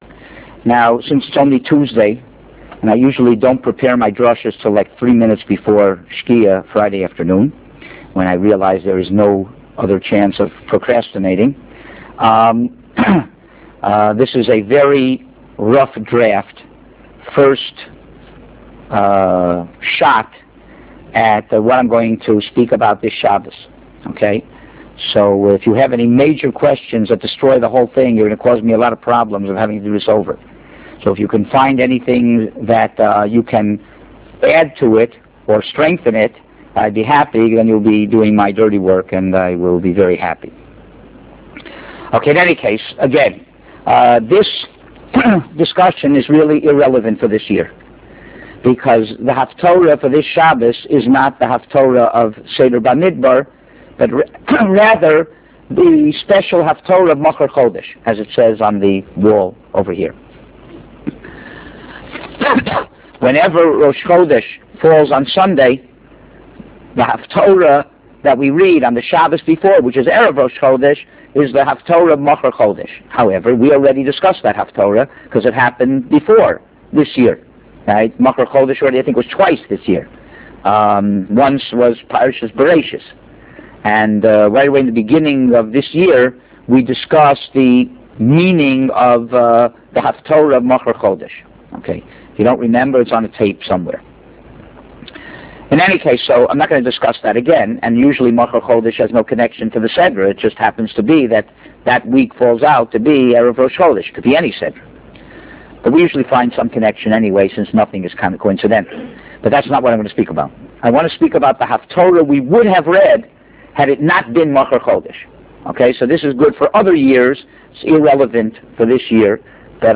Lecture is approx. 45 minutes.
Hear about the twelve tribes, finding your place in society, the sons of Aaron, and ways to give simple, yet fundamental respect to your parents - which really pays off. His insights are delivered with energy and humor.